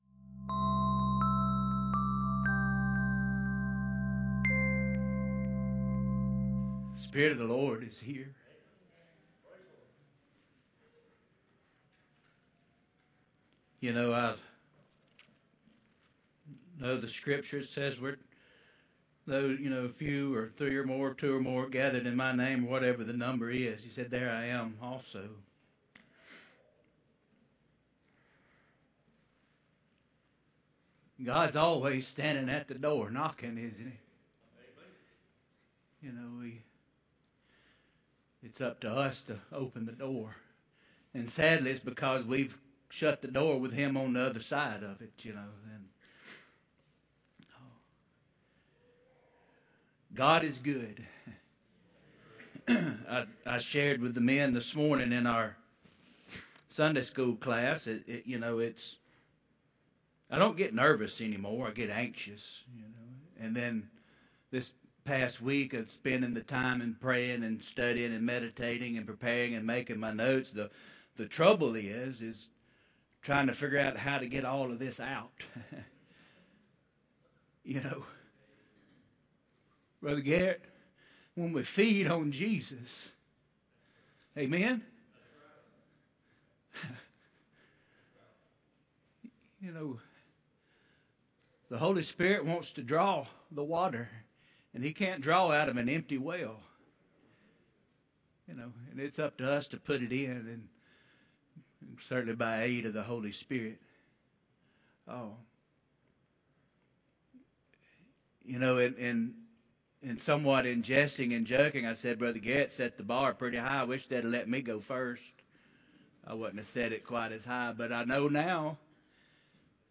The 2nd out of 8 total sermons in our 2025 joint service series on the